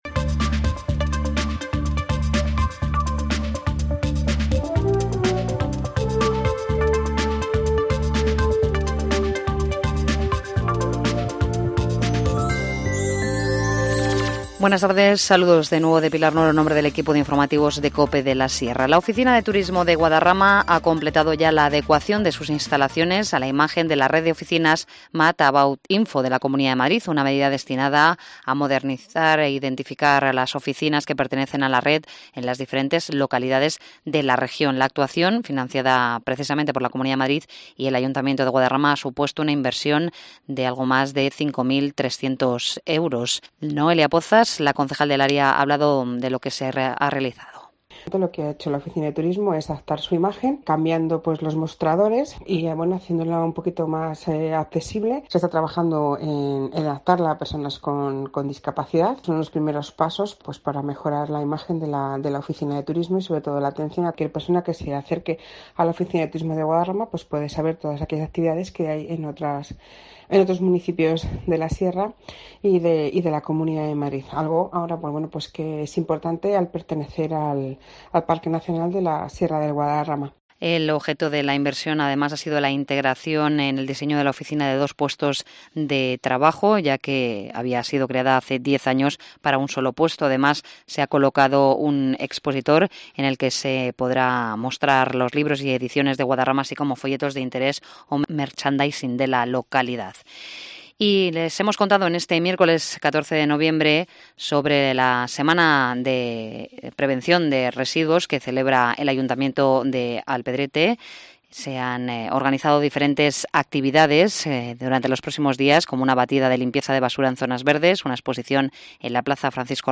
Informativo Mediodía 14 nov- 14:50h